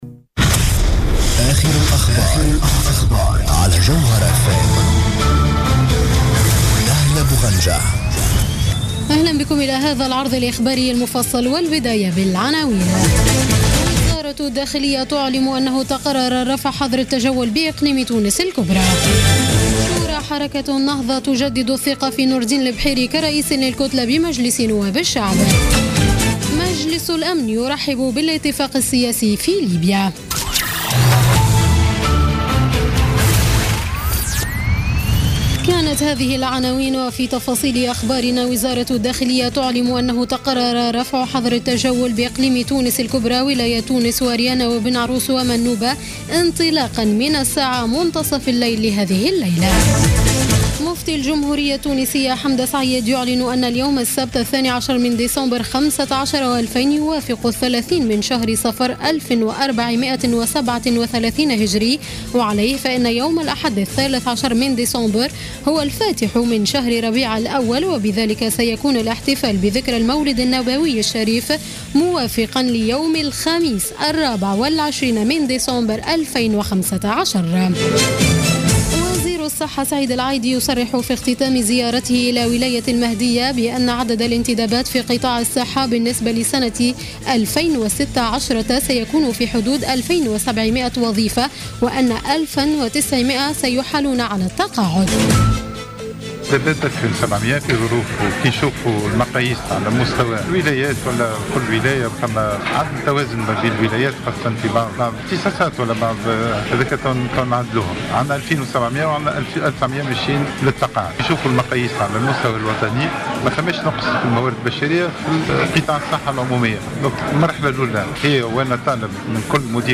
نشرة أخبار السابعة مساء ليوم السبت 12 ديسمبر 2015